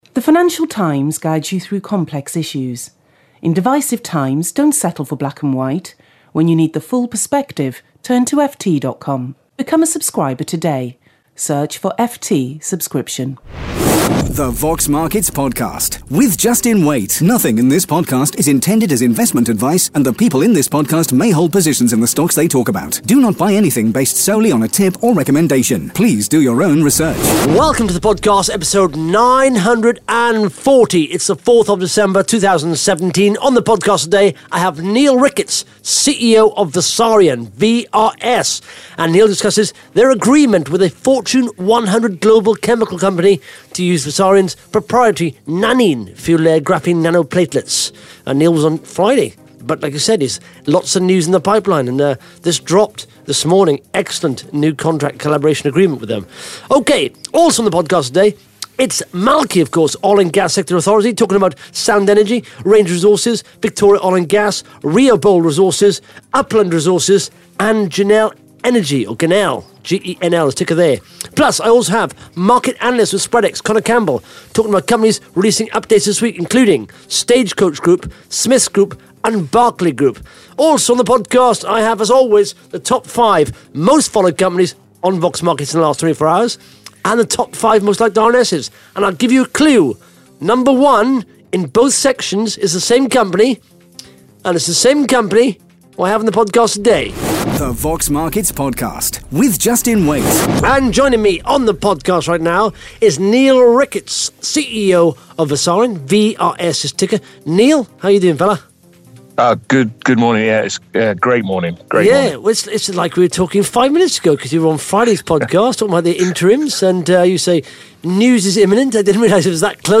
(Interview starts at 1 minute 37 seconds)